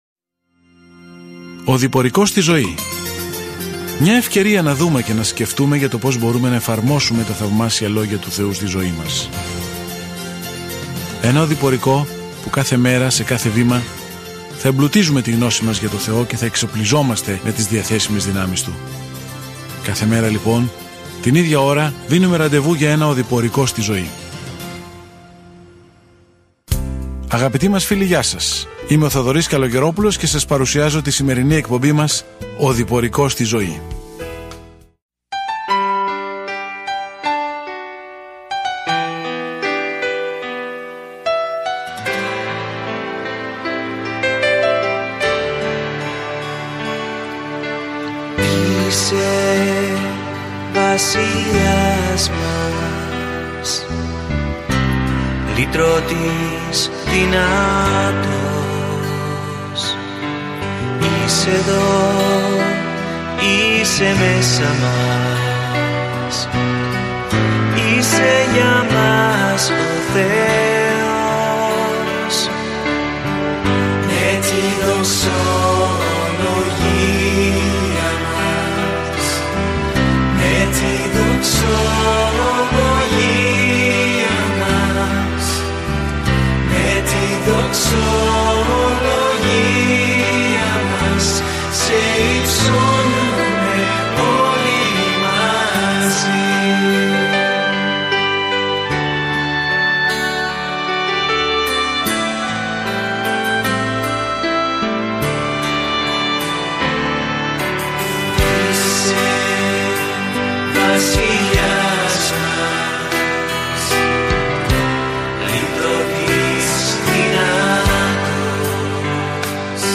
Καθημερινά ταξιδεύετε στον Ιωνά καθώς ακούτε την ηχητική μελέτη και διαβάζετε επιλεγμένους στίχους από τον λόγο του Θεού.